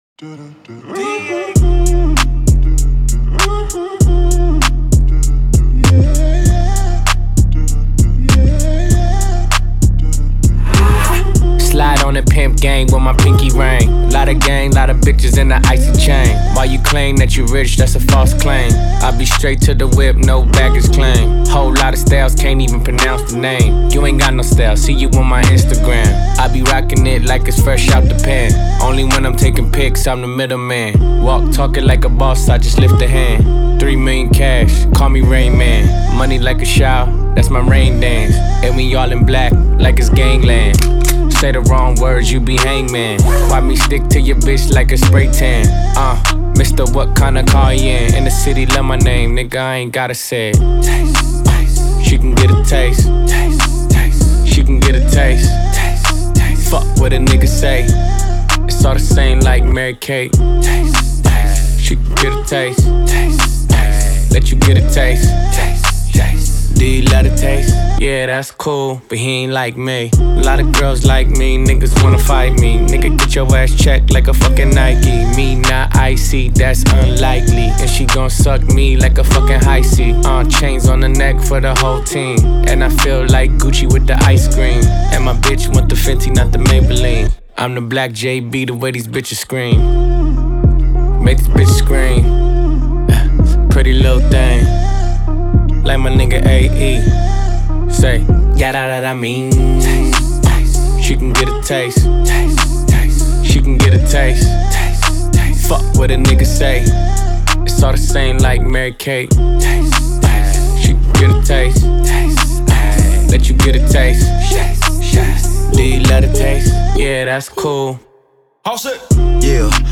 Genre: Rap / hip hop